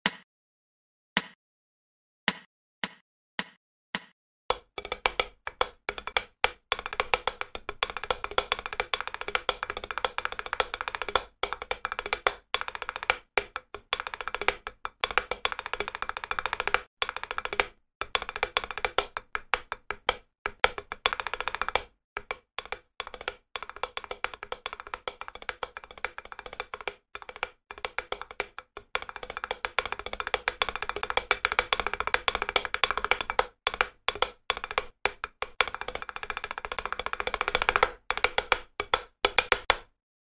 Все этюды записаны на педе для большей разборчивости и возможно помогут тем кто занимается по указанной книге самостоятельно.
Этюд №27 - содержит рудименты всех видов некратных и кратных роллов.
Размер 4/4, темп 108 bpm